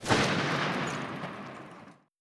AR1_ShootTail 01.wav